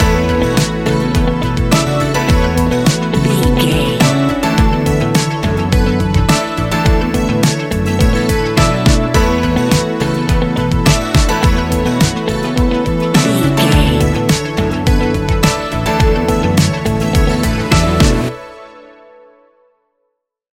Ionian/Major
ambient
downtempo
pads